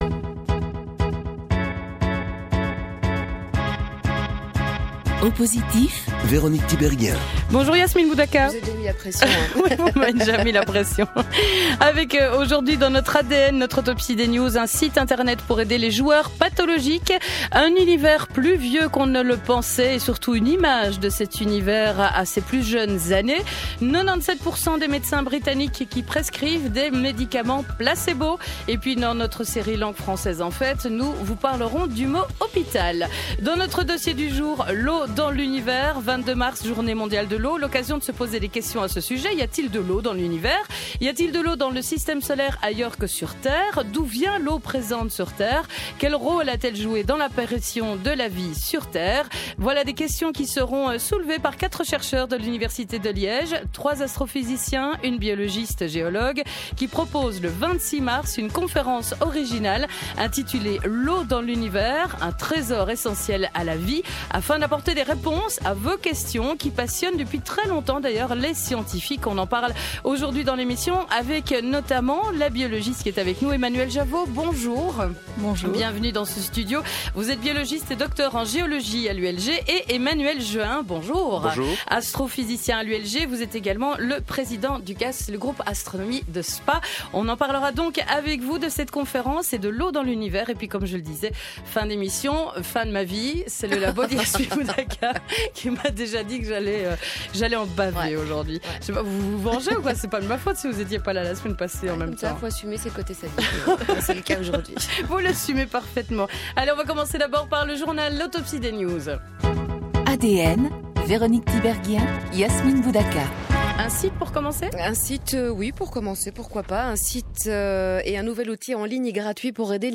Avec 4 chercheurs de l’Université de Liège dont 3 astrophysiciens et une biologiste-géologue.